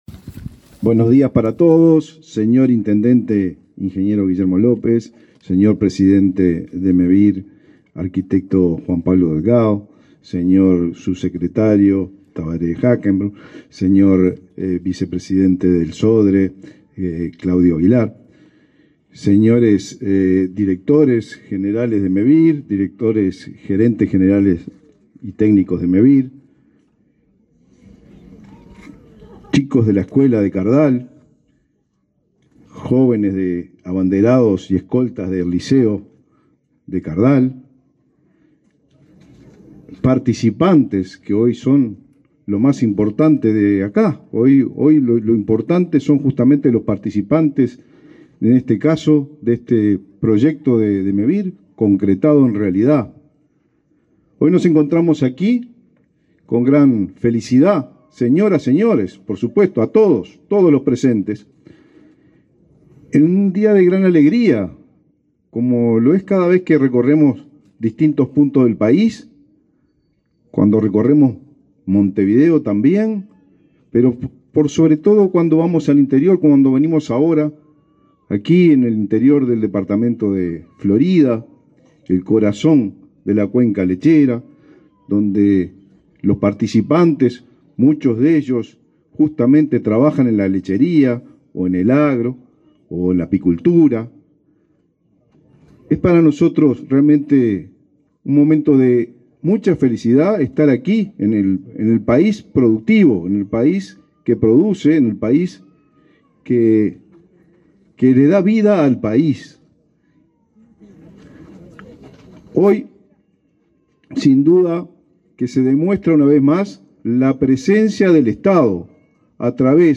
Palabra de autoridades en inauguración de Mevir
Palabra de autoridades en inauguración de Mevir 14/09/2023 Compartir Facebook X Copiar enlace WhatsApp LinkedIn El ministro de Vivienda, Raúl Lozano, y el presidente de Mevir, Juan Pablo Delgado, participaron en la inauguración de 36 viviendas en la localidad de Cardal, departamento de Florida.